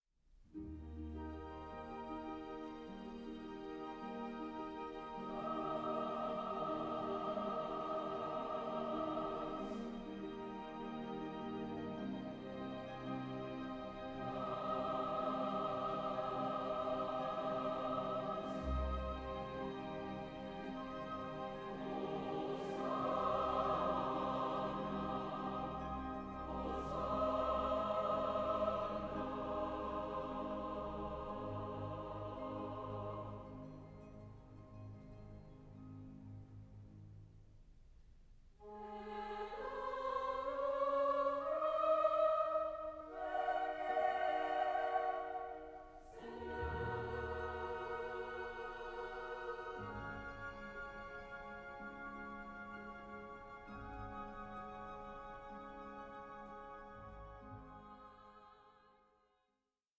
Légende dramatique en quatre parties
soprano
tenor
bass